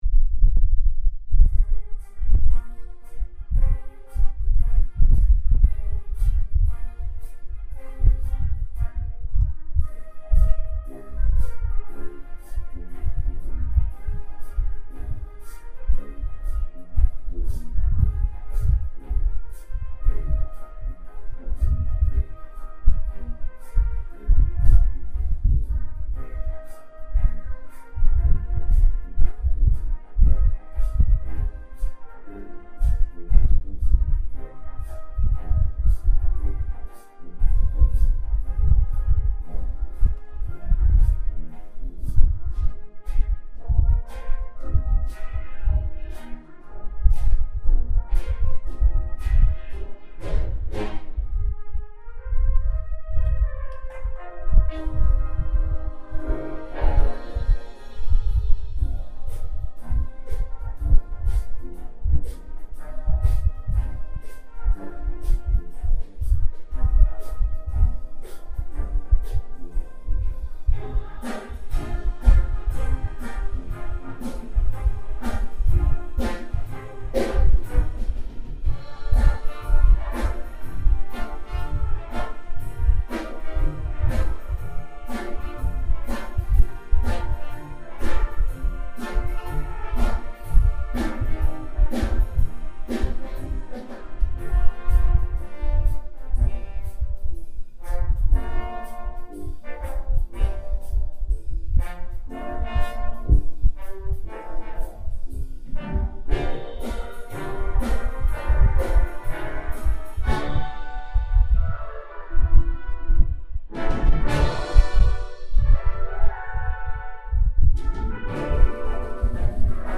Singin' in the Rain - Brass Ensemble